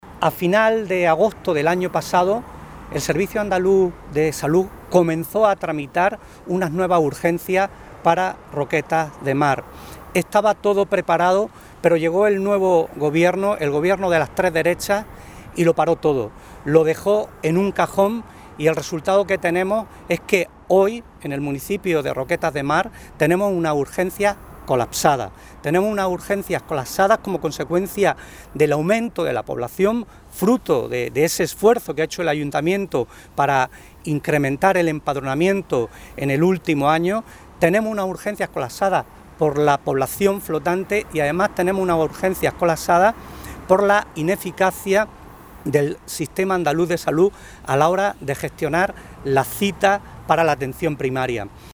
190806 Corte PSOE JLST Atención a medios. Colapso urgencias Roquetas